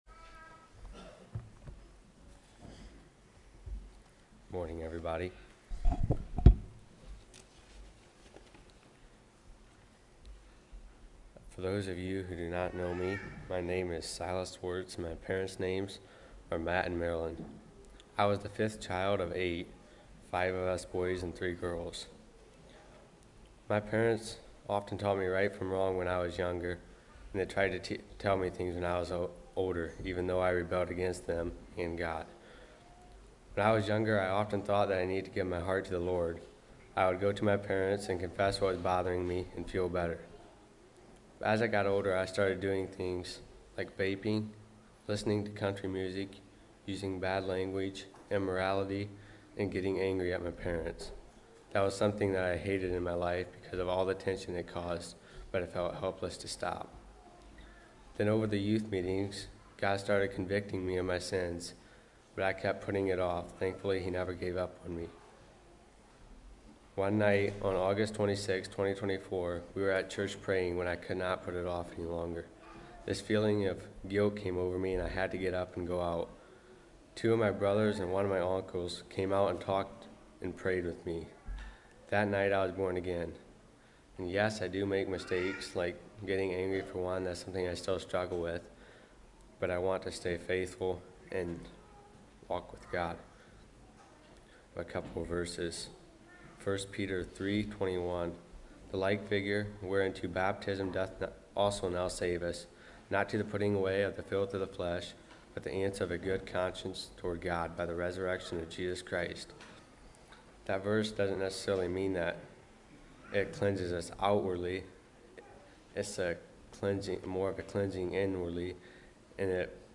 Baptismal Testimony